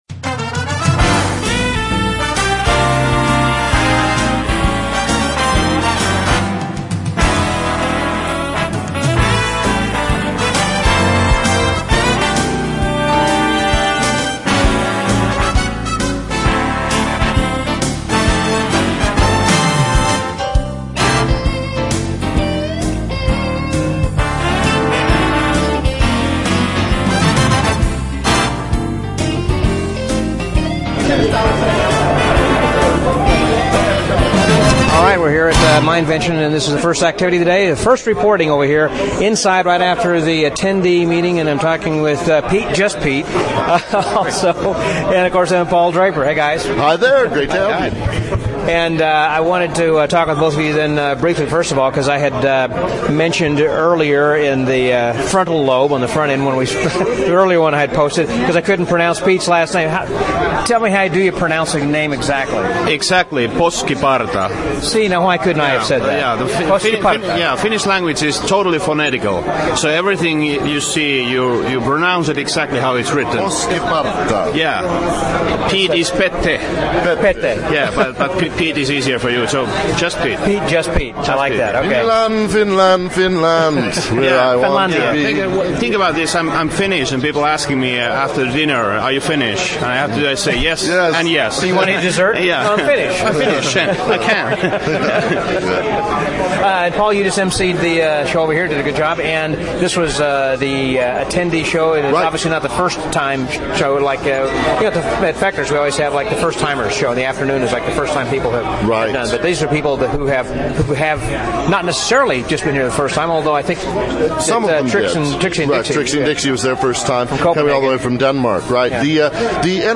After a little bit of a travel difficulty due to a layover in San Diego, I finally arrived in Las Vegas for the first day of the annual MINDvention at the Palace Stations Hotel & Casino.